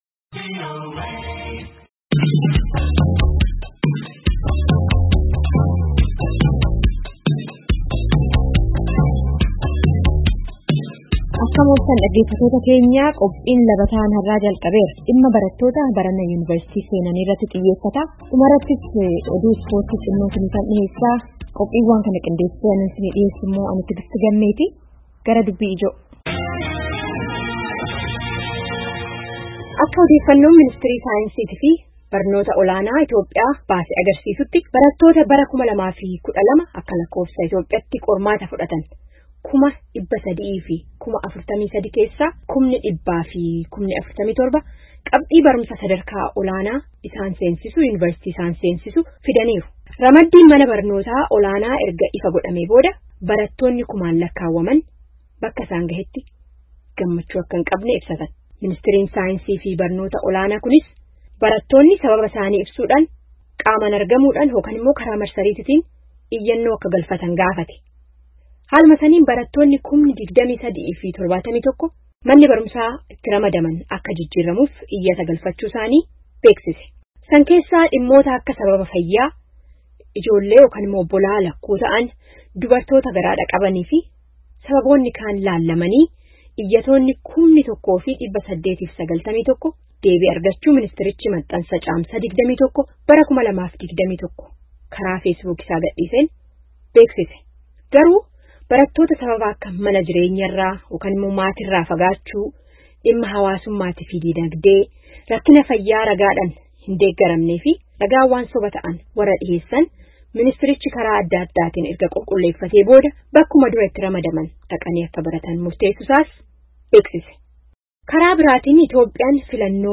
Barattoota laman dubbisnee, caqasaa